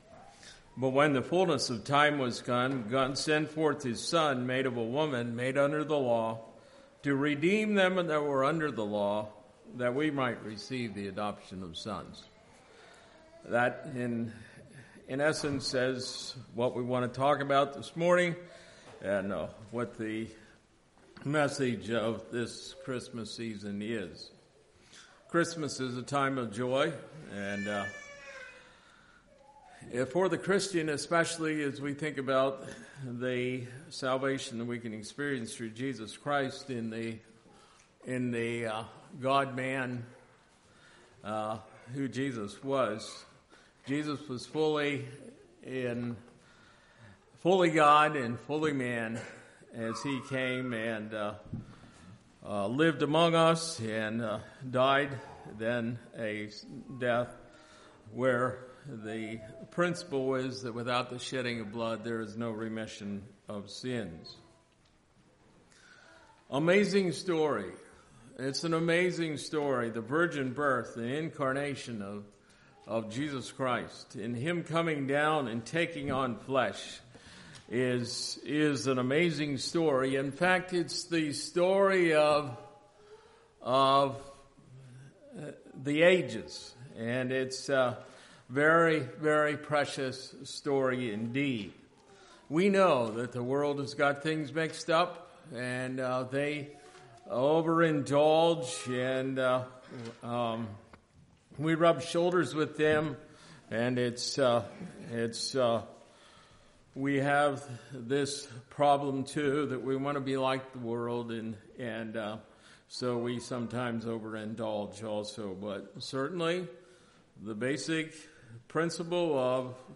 Sermons
Calvary | All Day Meetings 2023